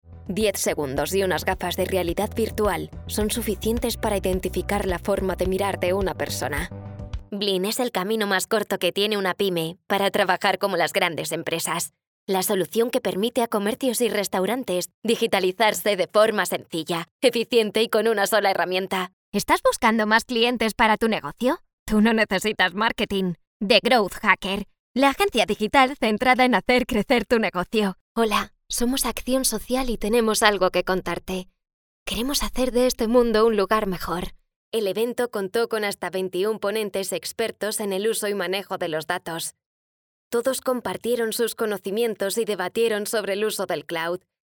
Jeune, Urbaine, Cool, Fiable, Naturelle
Corporate